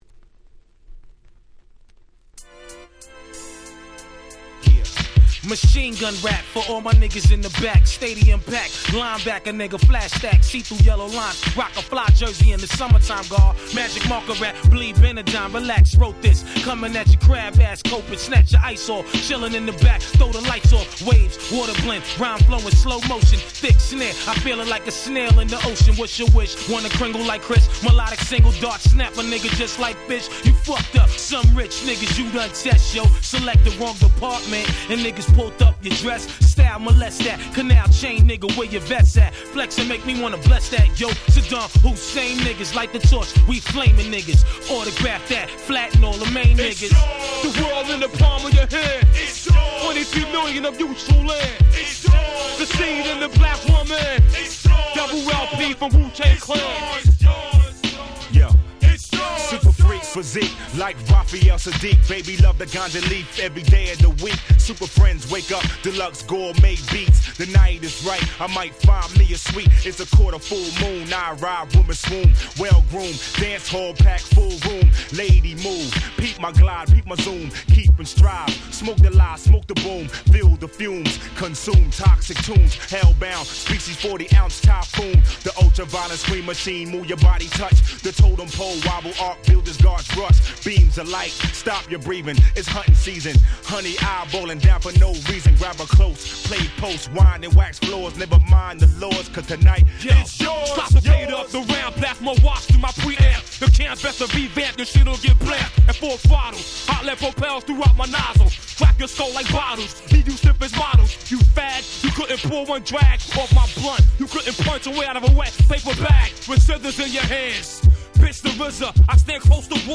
97' Smash Hit Hip Hop !!
SolidなBeatがクソ格好良い超絶クラシック！！
Boom Bap ブーンバップ 90's